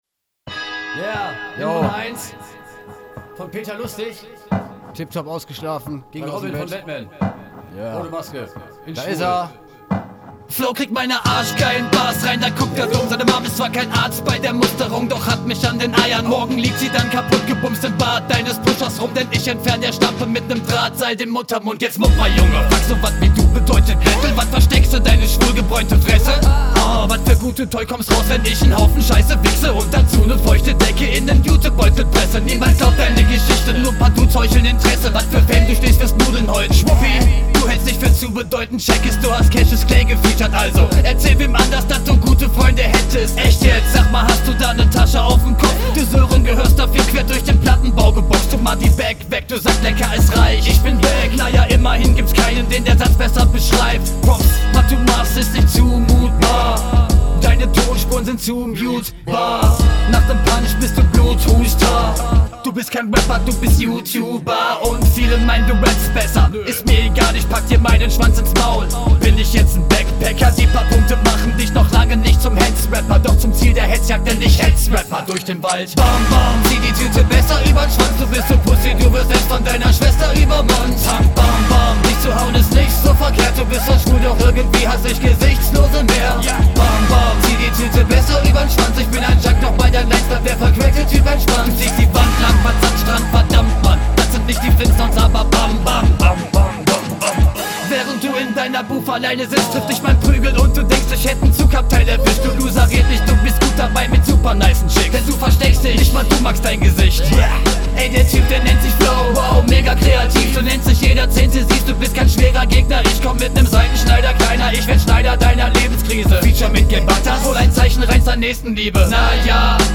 Kommst geil rein, wie man es erwartet, Flow, Reime, Stimme - geil!
^^ Die Mische ist sehr angenehm. Klar verständlich. Die Doubles schön präzise synchronisiert.